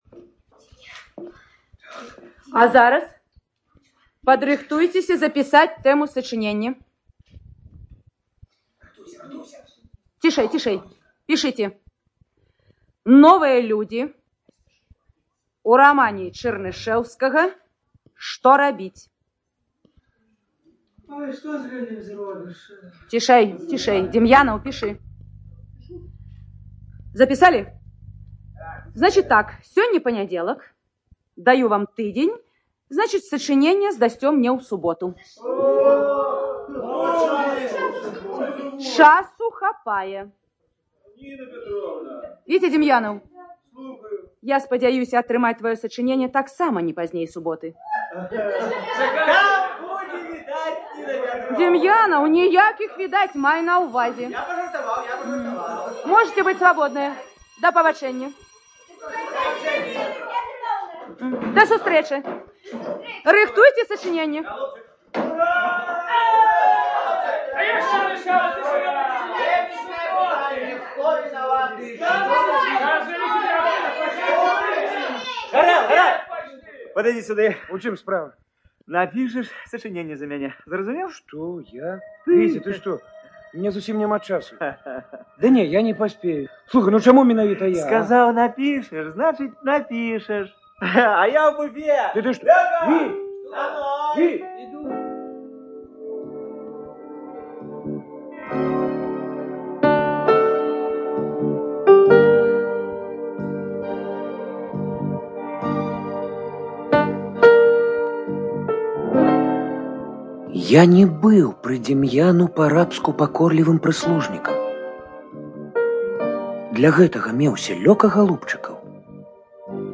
ЖанрРадиоспектакли на белорусском языке